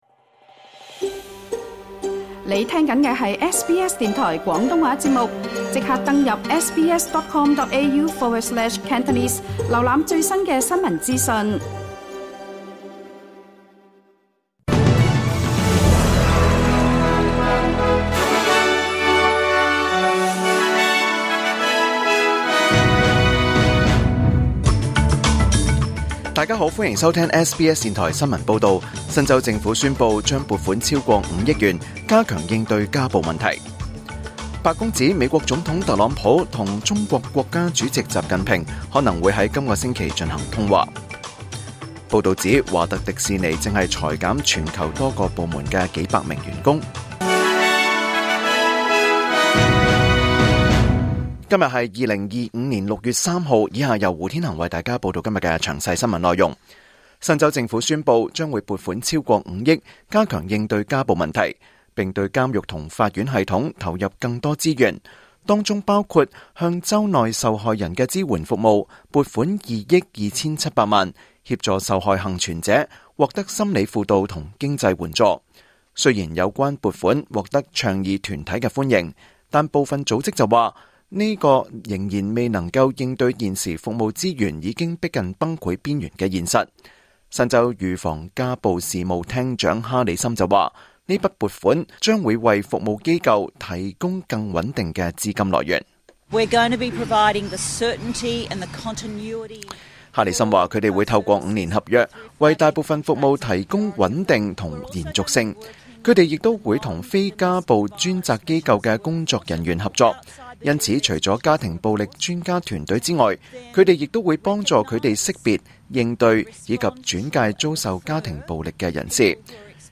2025年6月3日SBS廣東話節目詳盡早晨新聞報道。